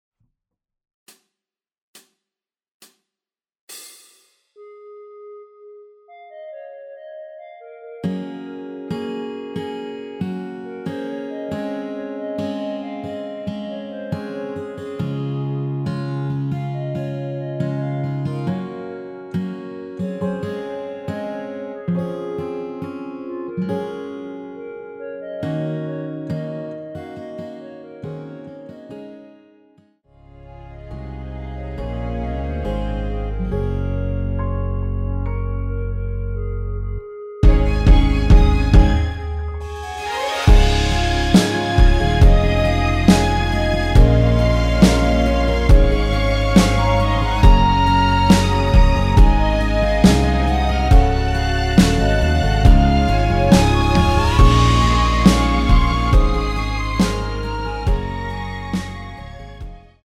전주 없이 시작하는 곡이라서 시작 카운트 만들어놓았습니다.(미리듣기 확인)
원키에서(+5)올린 (1절앞+후렴)으로 진행되는 멜로디 포함된 MR입니다.
Db
앞부분30초, 뒷부분30초씩 편집해서 올려 드리고 있습니다.
중간에 음이 끈어지고 다시 나오는 이유는